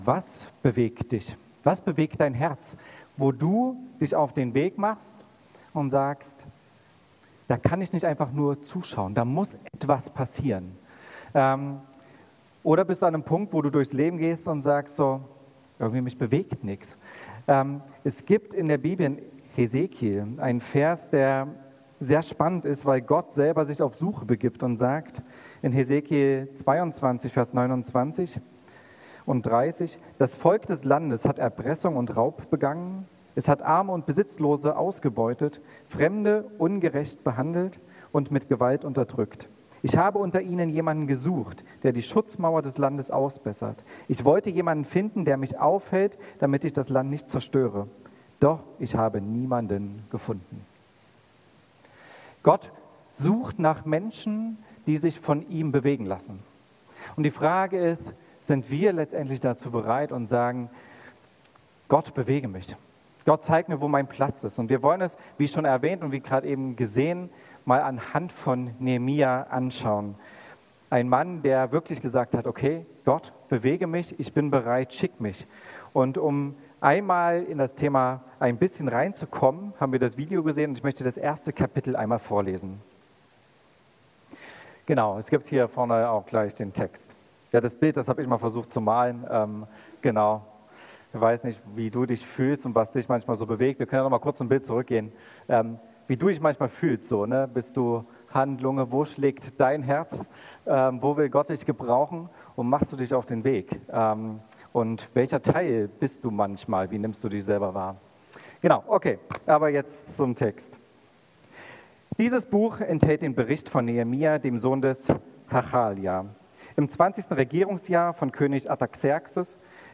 Gottesdienst mit Abendmahl